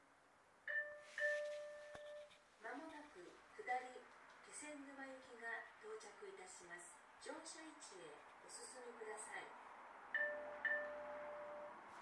この駅では接近放送が設置されています。
接近放送普通　気仙沼行き接近放送です。